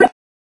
weapon-get.mp3